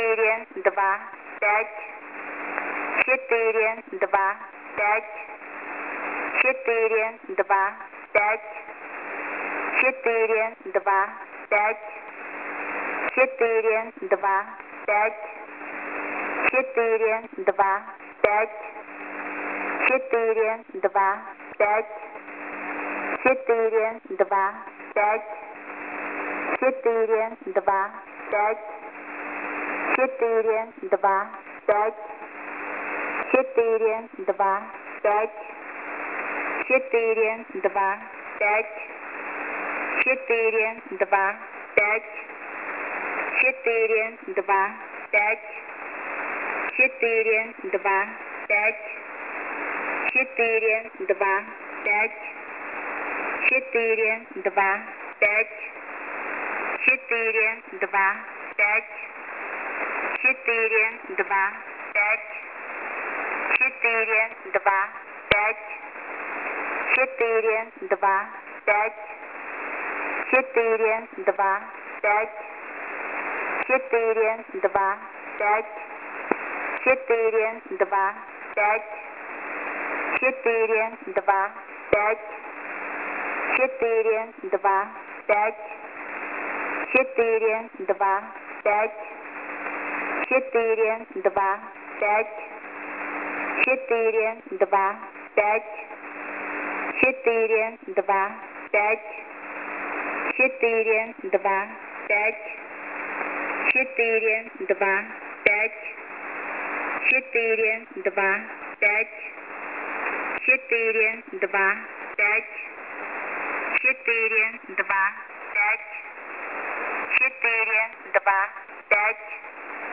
Mode: USB + Carrier